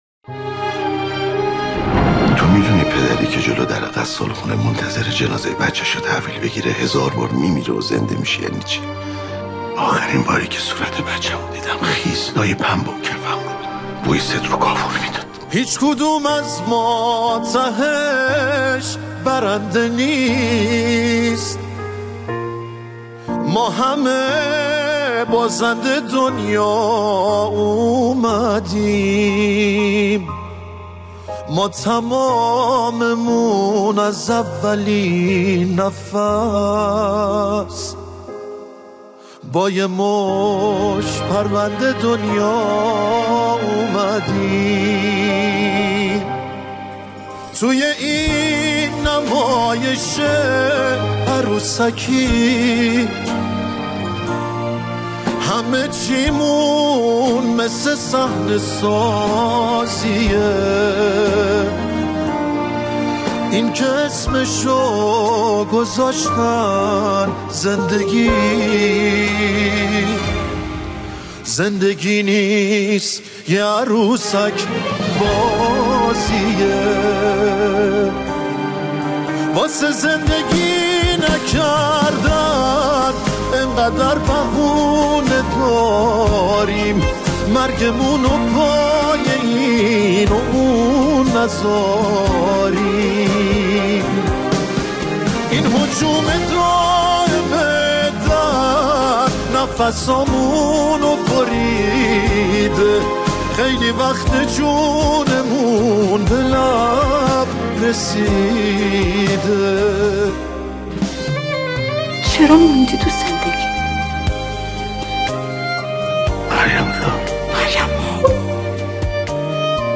آهنگ تیتراژ سریال
غمگین